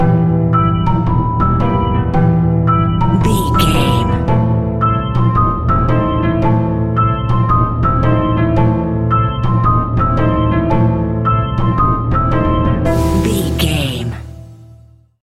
Aeolian/Minor
scary
ominous
haunting
eerie
piano
strings
organ
flute
percussion
spooky
horror music